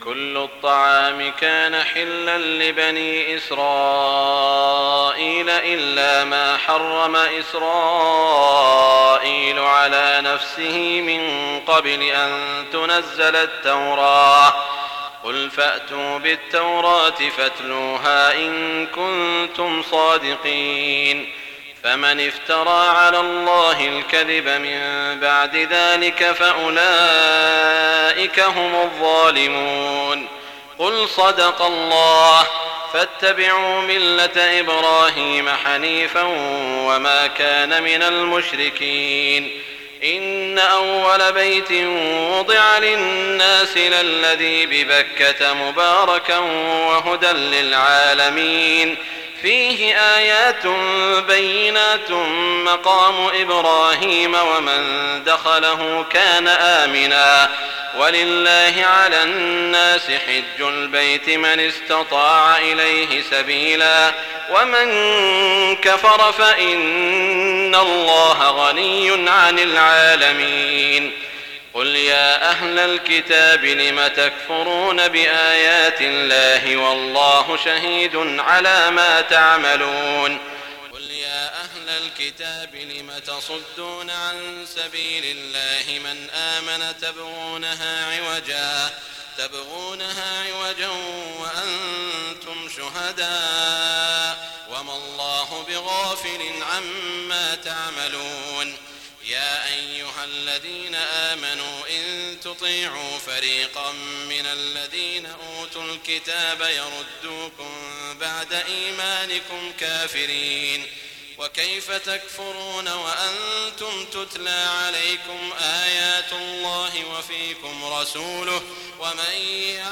تهجد ليلة 24 رمضان 1419هـ من سورة آل عمران (93-185) Tahajjud 24 st night Ramadan 1419H from Surah Aal-i-Imraan > تراويح الحرم المكي عام 1419 🕋 > التراويح - تلاوات الحرمين